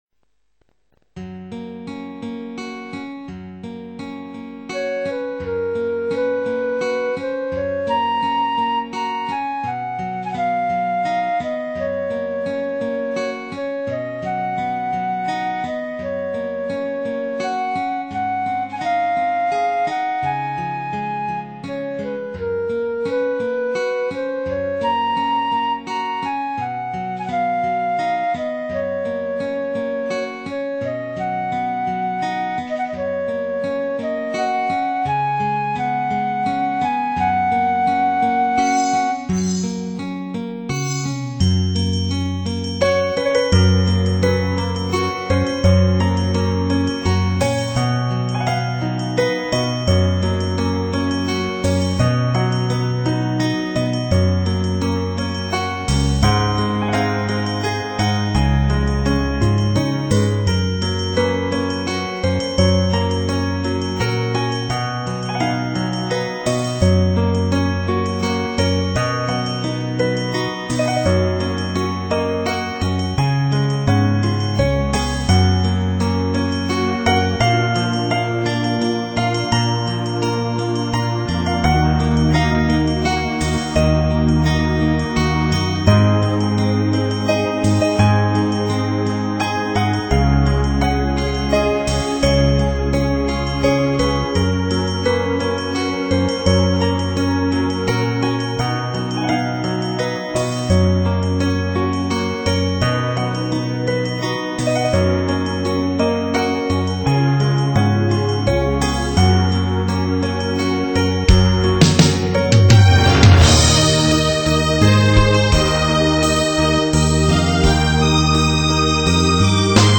轻松的旋律，明快的节奏，混合着若有若无的咖啡香味在空气中回旋。